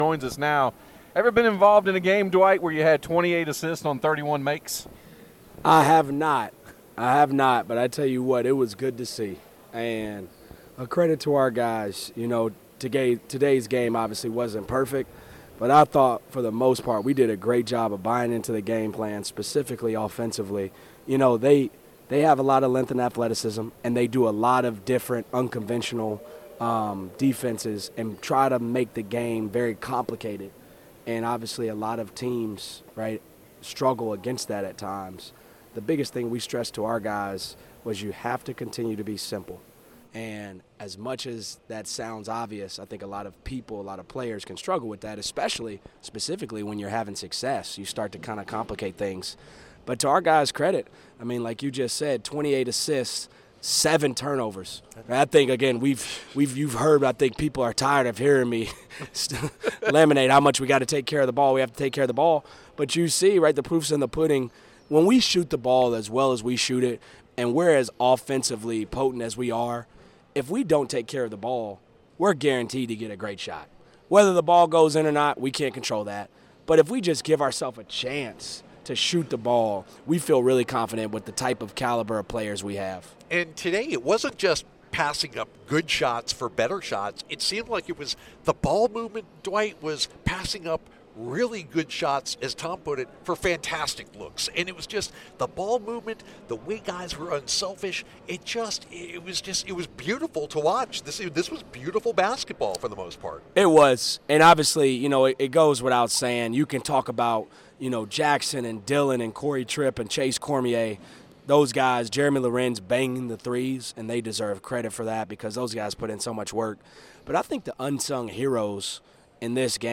December 10, 2023 Download Listen Now All Categories Postgame Audio All Sports Men's Basketball Women's Basketball Loading More Podcasts...